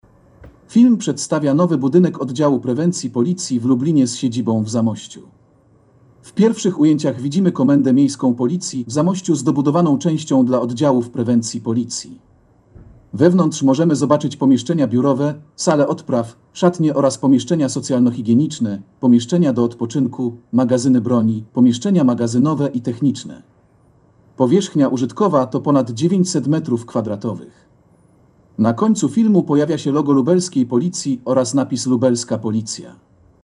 Nagranie audio Audiodeskrypcja Filmu Oddział Prewencji Policji z siedzibą w Zamościu